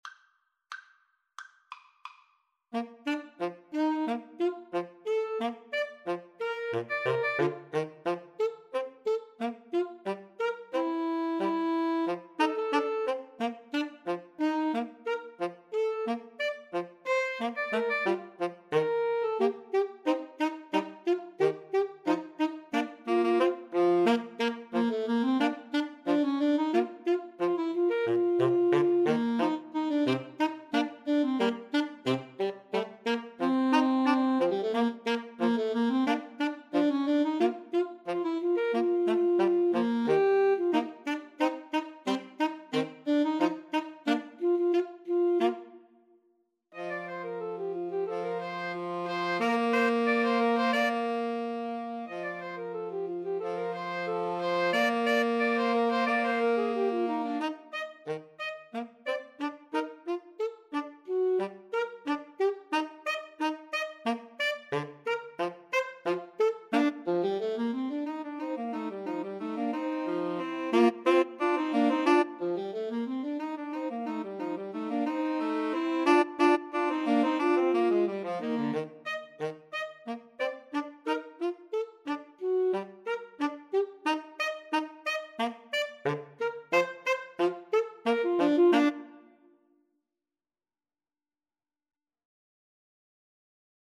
Bb major (Sounding Pitch) (View more Bb major Music for Woodwind Trio )